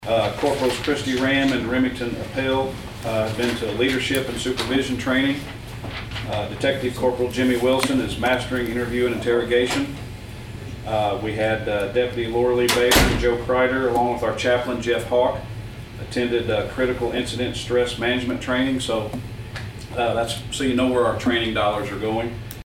St. Francois Co., Mo. (KFMO) - Sheriff Jeff Crites gave the St. Francois County Commission an update on the latest operations of the Sheriff's Department at the commission's meeting on Tuesday.